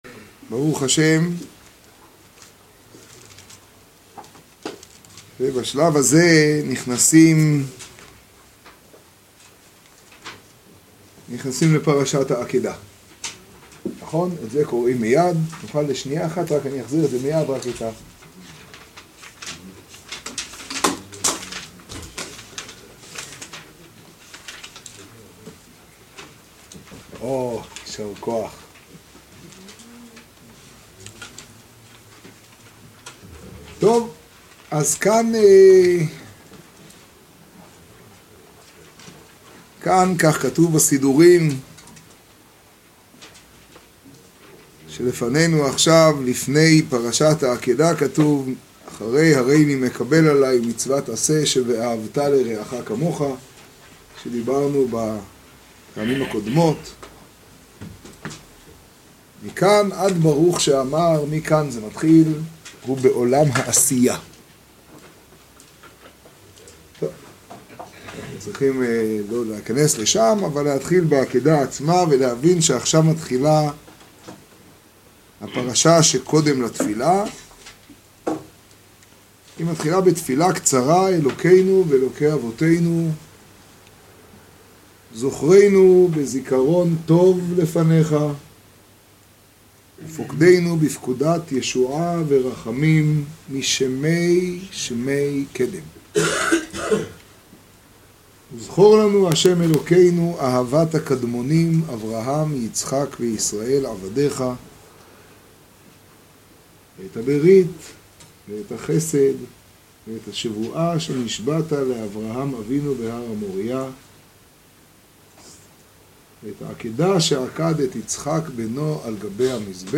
השיעור בחצור, תשעד.